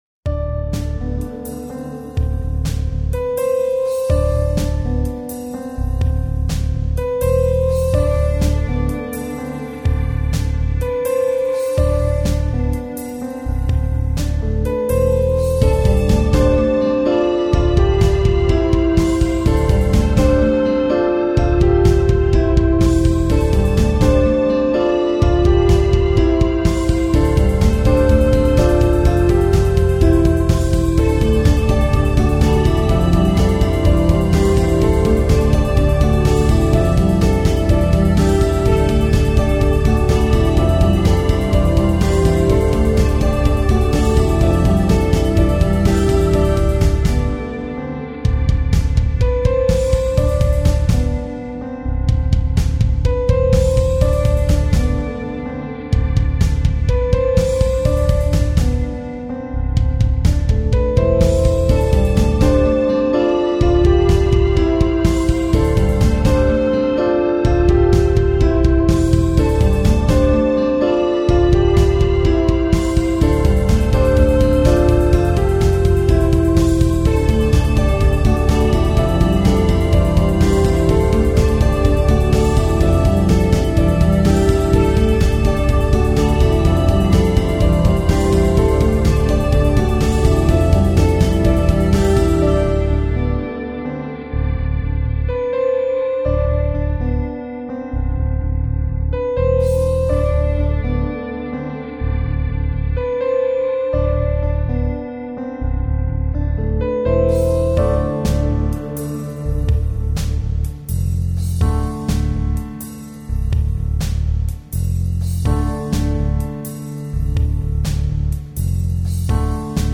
Contemplative Rock